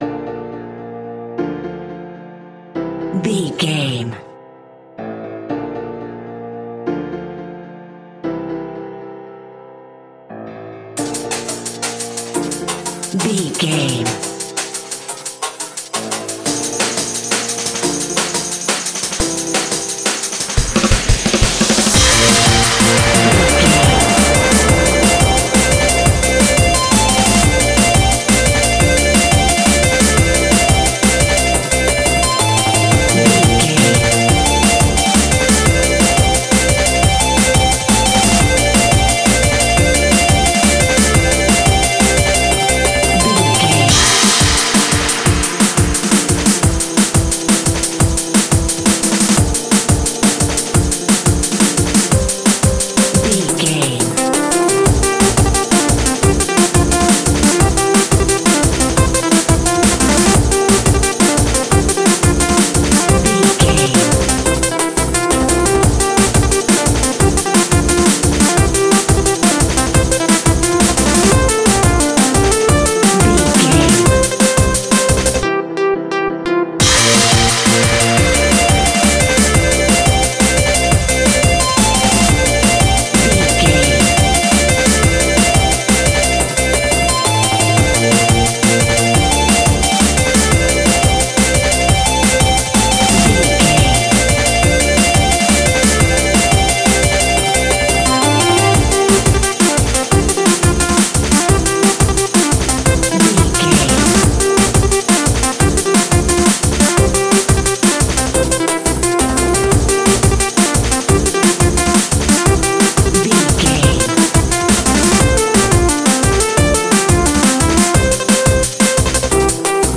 Aeolian/Minor
D
Fast
futuristic
hypnotic
industrial
frantic
aggressive
dark
drum machine
piano
synthesiser
Drum and bass
electronic
sub bass
synth leads